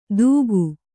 ♪ dūgu